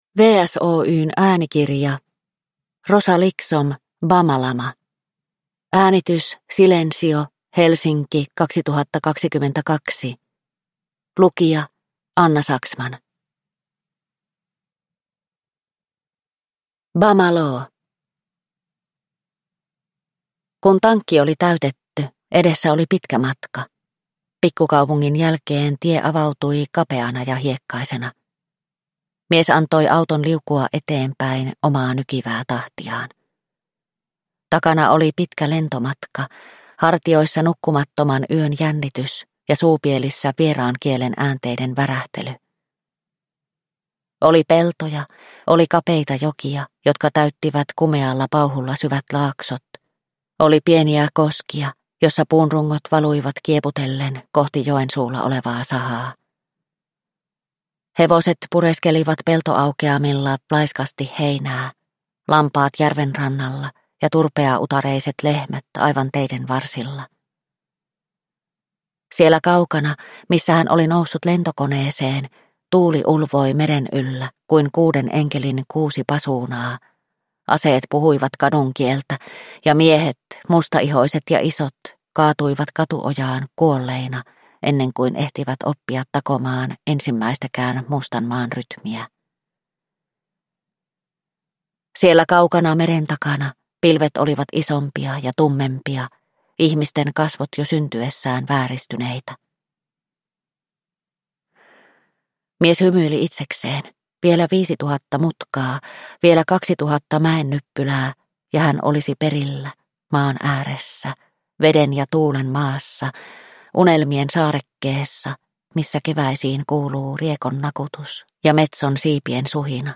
BamaLama – Ljudbok – Laddas ner